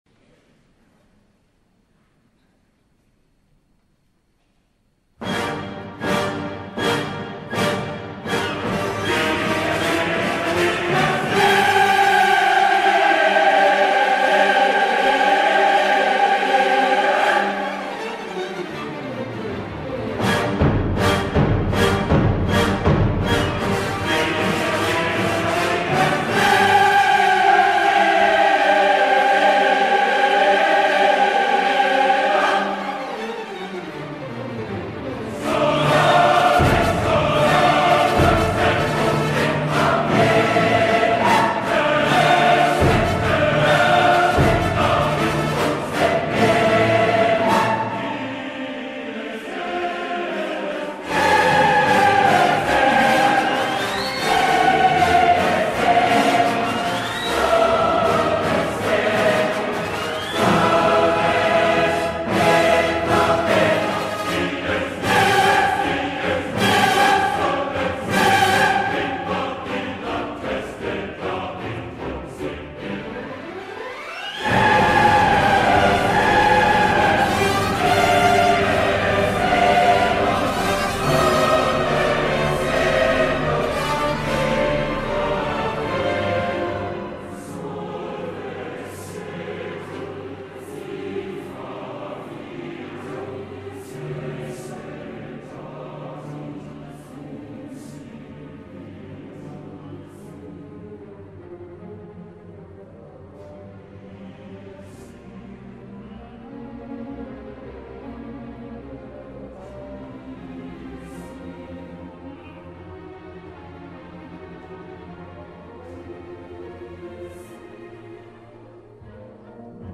Today, we are going to learn about music that makes us feel SCARED.
Dies Irae - BBC Prom - Verdi Requiem.mp3